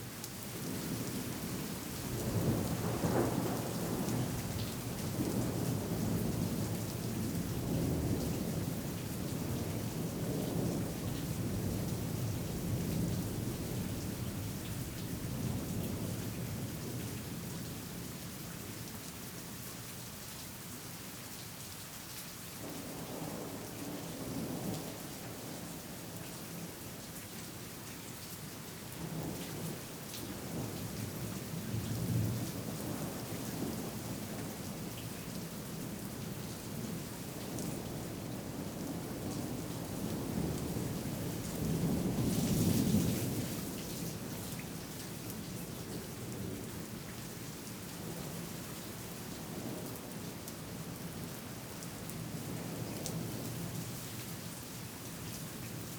Weather Evening Medium Rain Thunder Rustling Trees ST450 03_ambiX.wav